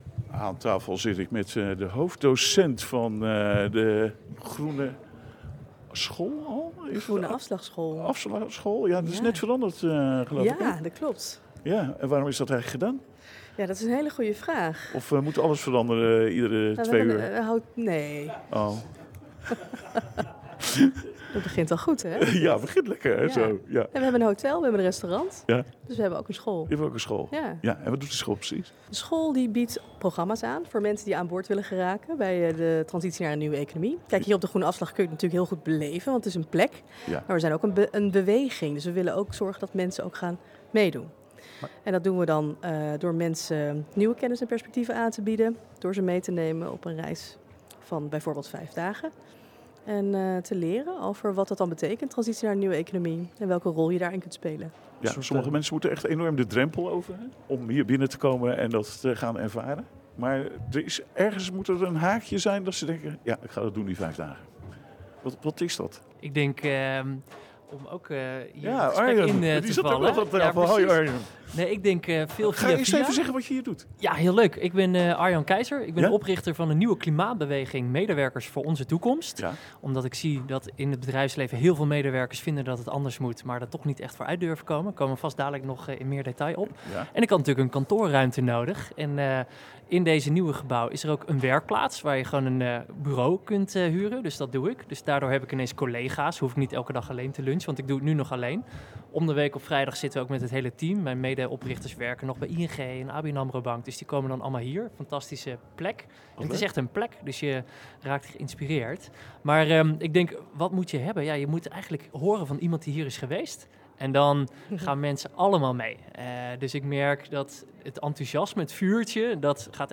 Luister naar dit deelinterview opgenomen tijdens de liveuitzending van 17 november 2025.